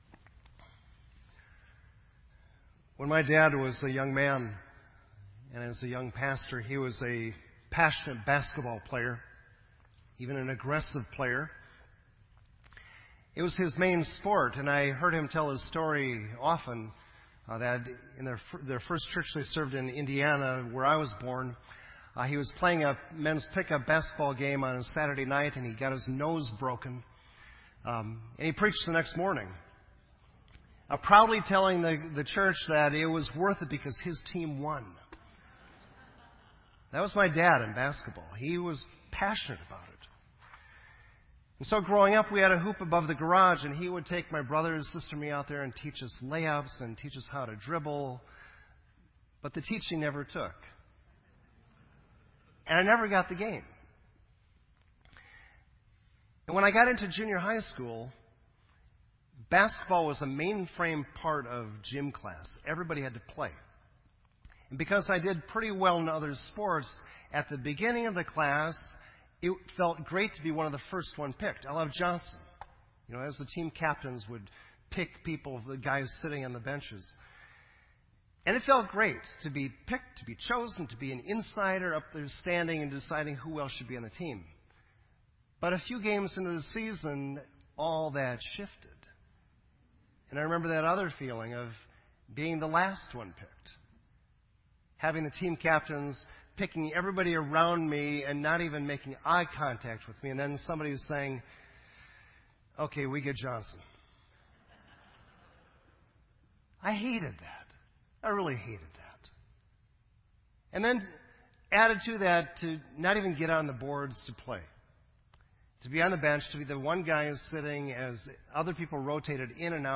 This entry was posted in Sermon Audio on February 15